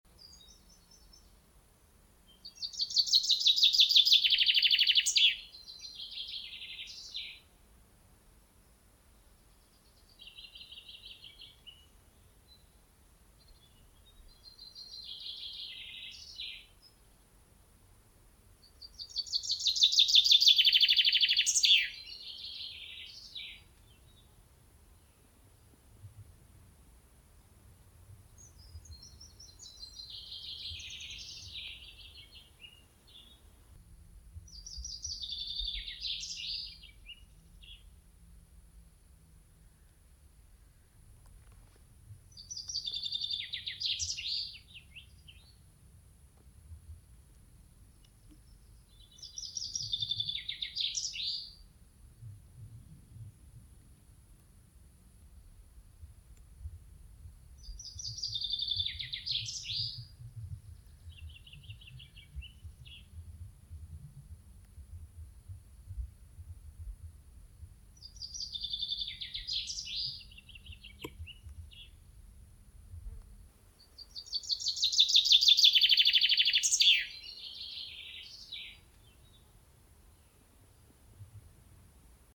Пение и другие звуки птицы зяблика слушать онлайн.
8. Настоящий голос зяблика (Fringilla coelebs)